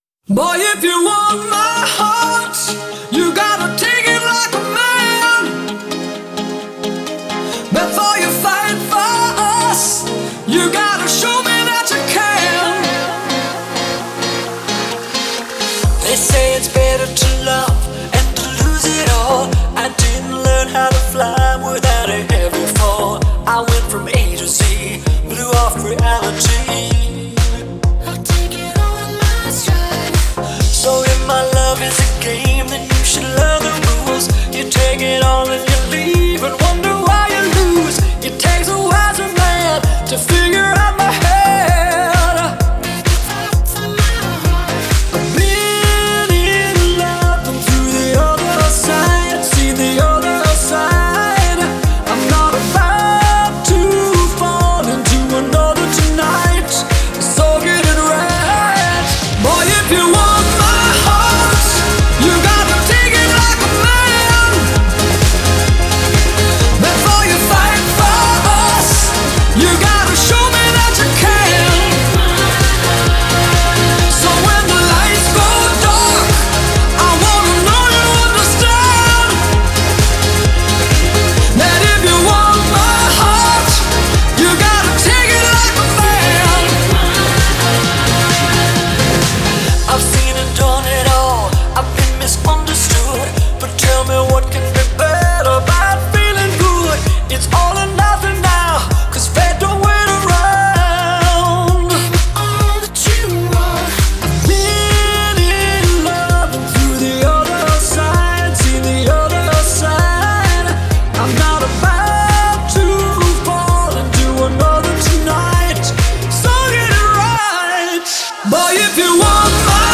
장르: Electronic, Pop
스타일: Dance-pop, House, Electro House, Ballad